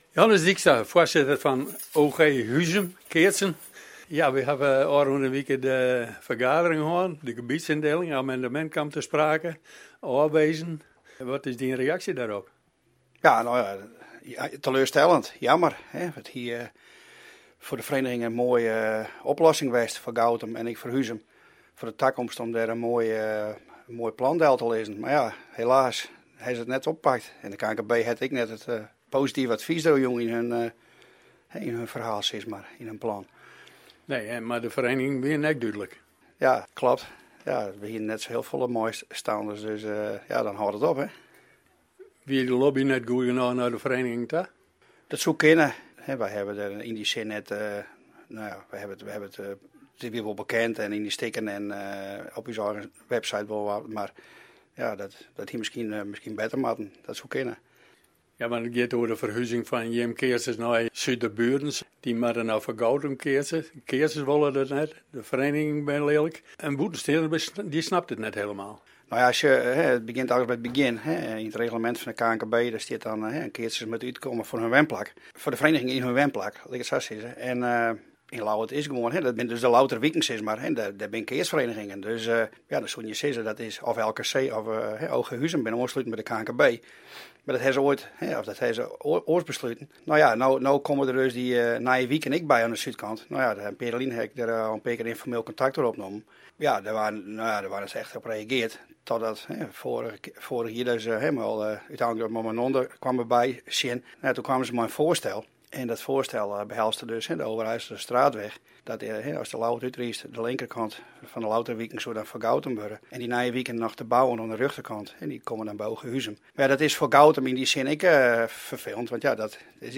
Op sportpark Nijlân organiseerde OG Huizum zondag 5 juli zijn jaarlijkse Huizumer Slager Partij. Een gesprek